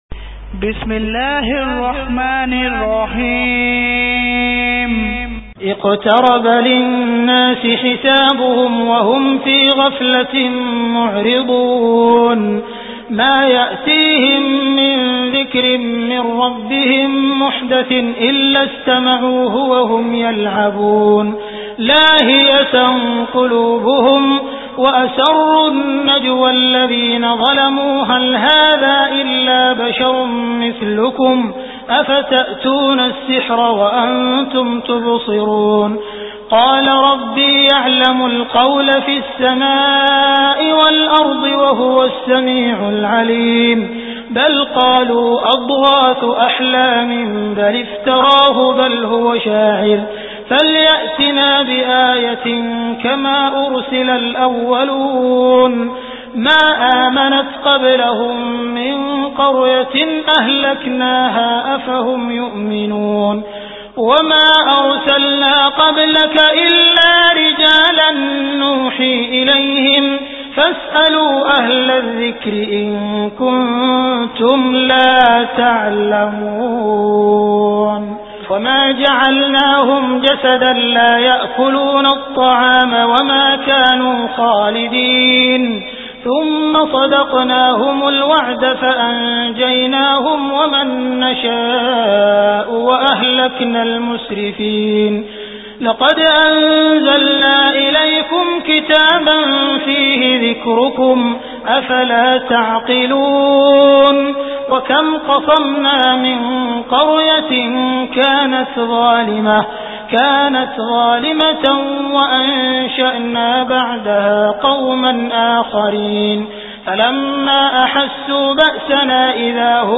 Surah Al Anbiya Beautiful Recitation MP3 Download By Abdul Rahman Al Sudais in best audio quality.